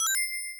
bubble.wav